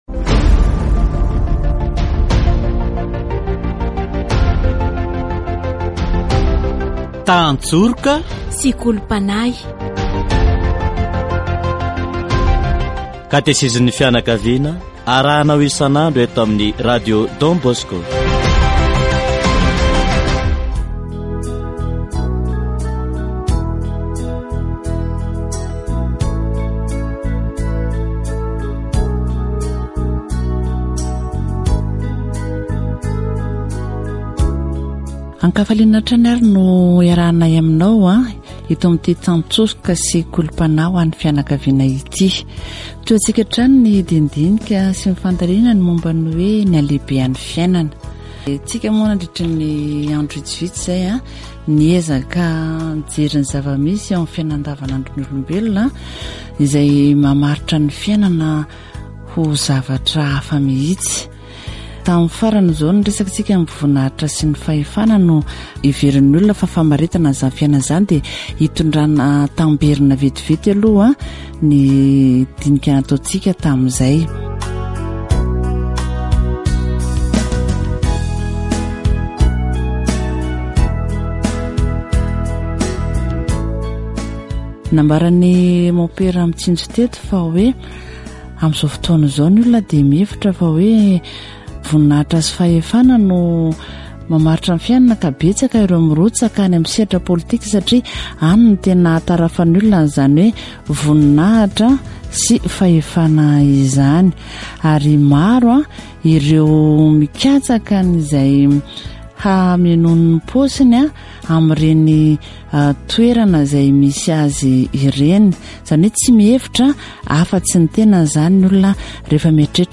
Ce qu'il faut, c'est une parfaite harmonie dans la pensée, la parole et l'action. Catéchèse sur l'amour et la vie